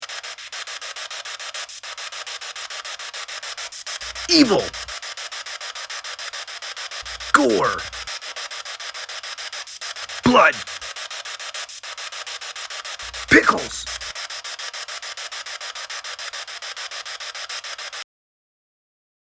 Within this sound, words can be discerned: Evil, Gore, Blood, Pickles.